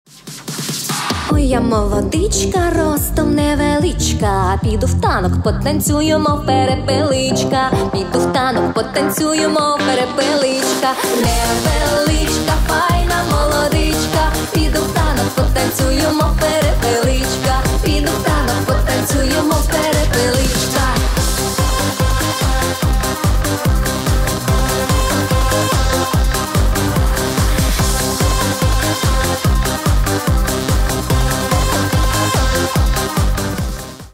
весёлые